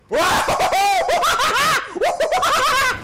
Папич УААауууАааа радостный крик